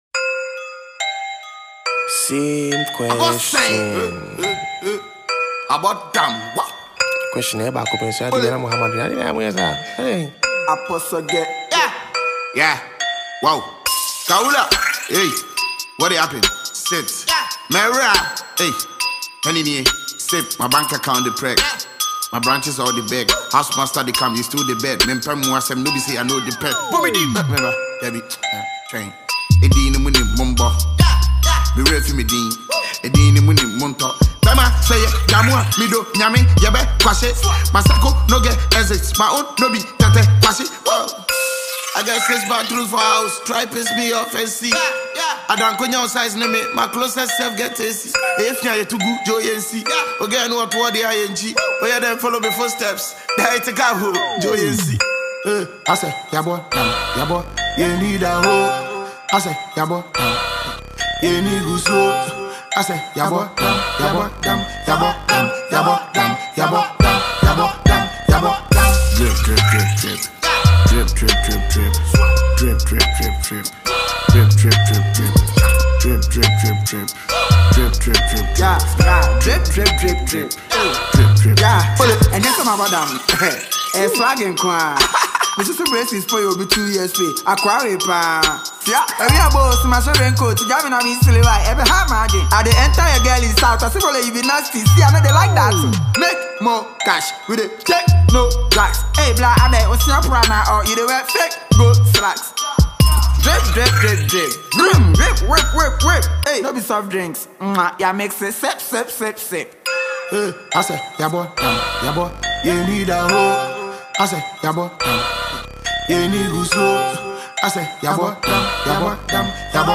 a thanksgiving song